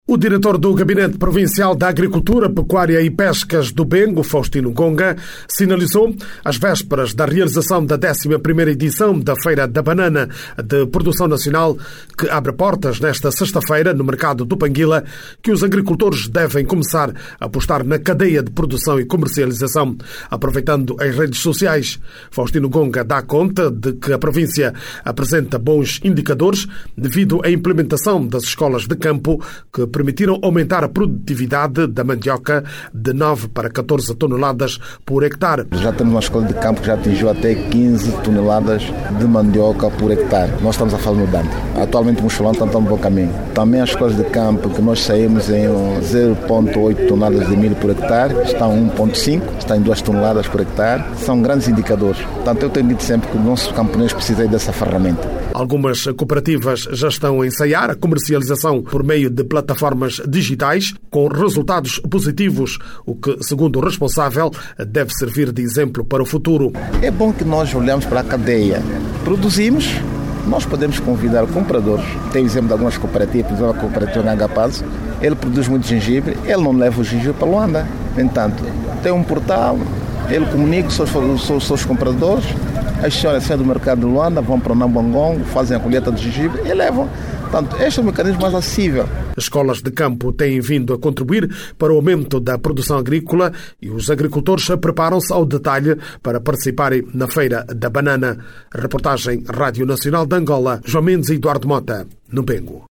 A organização fala em bons indicadores de produção local, não só da banana como da mandioca que este ano atingiu 14 hectares. Clique no áudio abaixo e ouça a reportagem